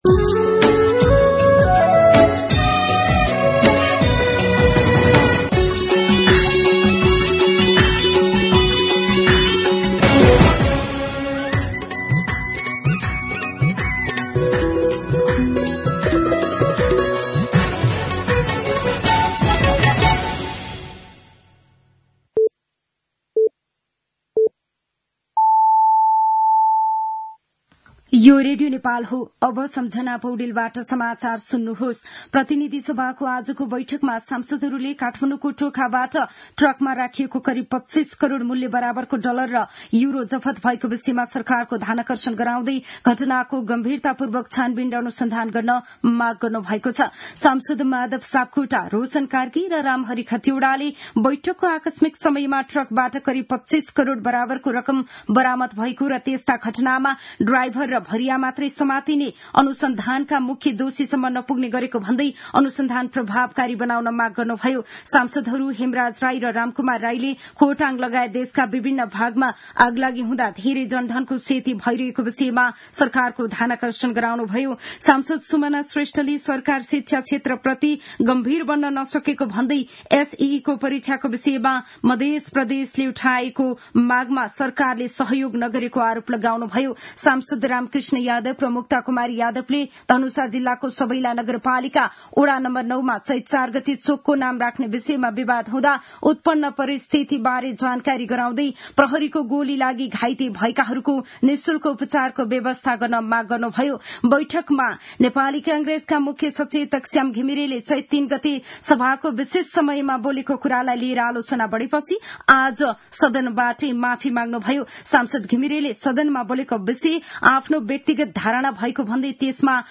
दिउँसो १ बजेको नेपाली समाचार : ६ चैत , २०८१